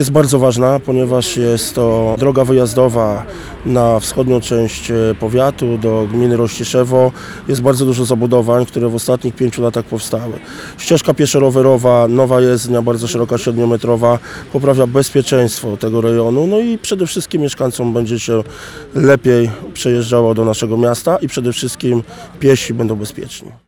Oficjalne otwarcie rozbudowanej ul. Głowackiego w Sierpcu
– mówił Starosta Powiatu Sierpeckiego Przemysław Burzyński.